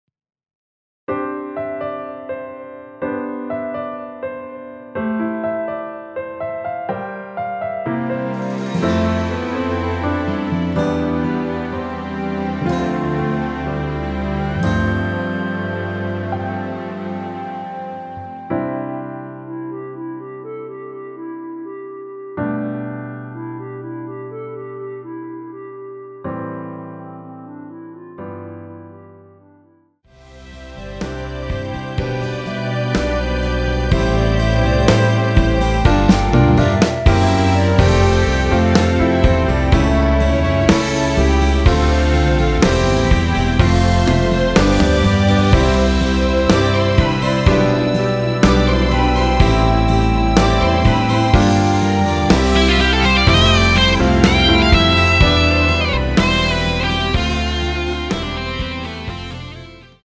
원키에서(-1)내린 멜로디 포함된 MR입니다.(미리듣기 확인)
앞부분30초, 뒷부분30초씩 편집해서 올려 드리고 있습니다.